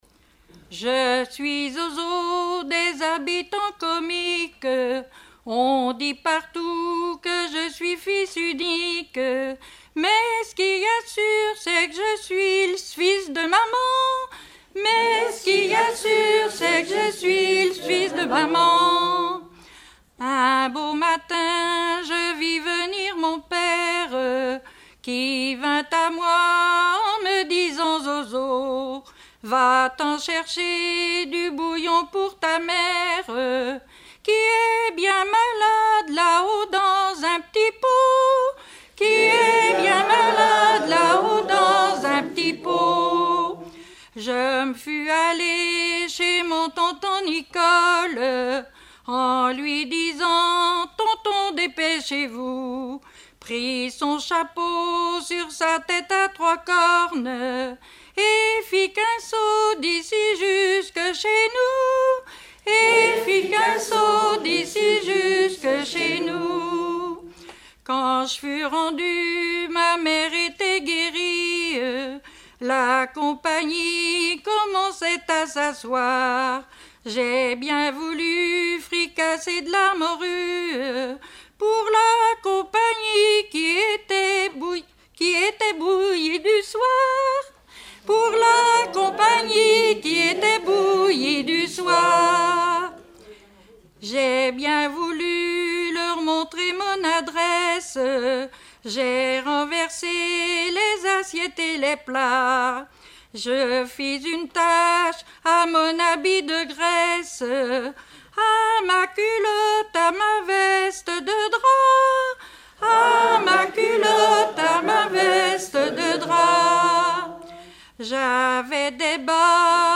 Genre strophique
Chansons traditionnelles et populaires
Pièce musicale inédite